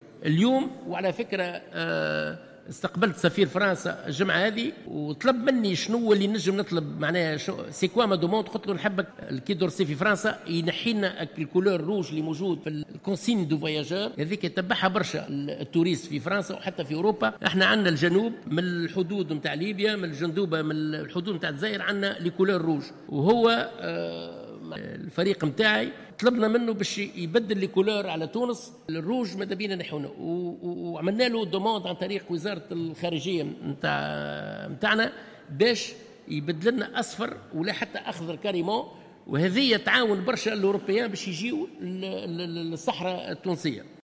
وشدّد الطرابلسي، في ردوده على استفسارات النواب خلال جلسة عامة بمجلس نواب الشعب لمناقشة مشروع ميزانة الوزارة لسنة 2019، على أنّ السّياحة البديلة تحظى بأهميّة كبيرة في المنظومة السّياحية التّونسية وتسجل دائما نسبة امتلاء محترمة، مؤكدا أن الوزارة ستعمل على دفع السياحة البديلة والداخلية.